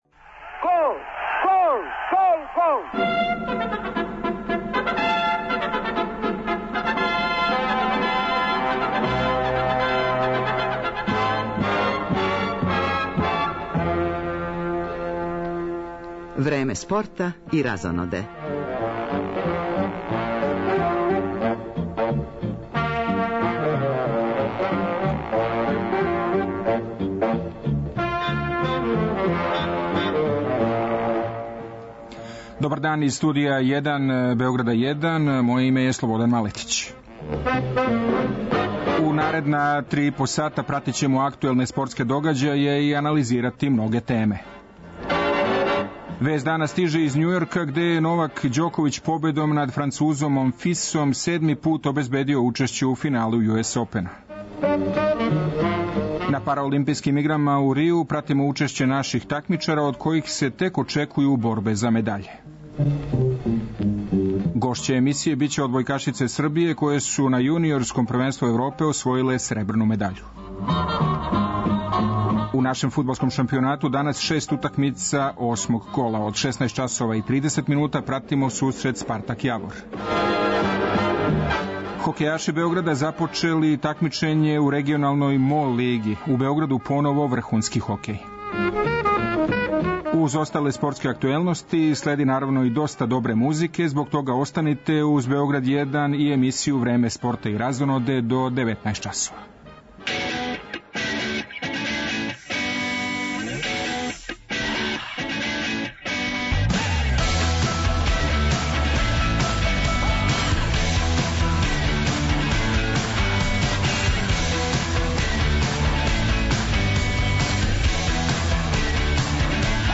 Гошће емисије биће одбојкашице Србије, које су на јуниорском првенству Европе освојиле сребрну медаљу.